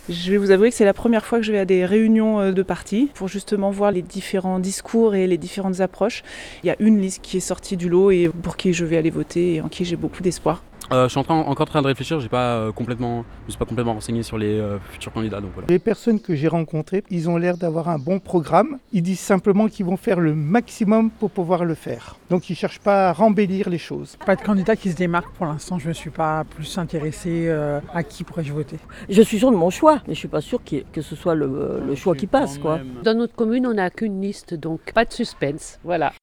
La Rédaction est allée vous poser la question dans la Vallée de l'Arve.